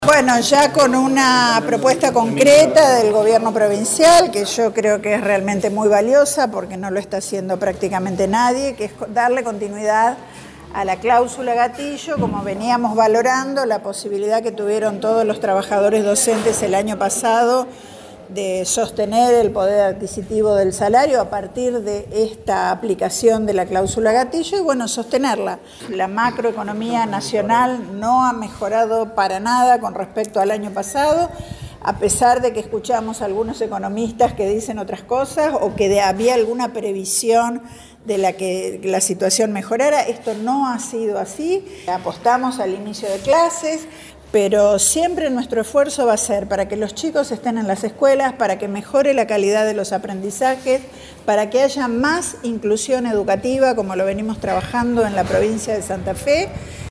La ministra de Educación de la Provincia de Santa Fe, Claudia Balagué, dialogó con la prensa al término del segundo encuentro paritario.